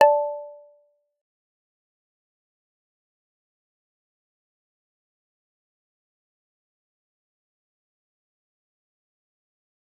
G_Kalimba-D5-f.wav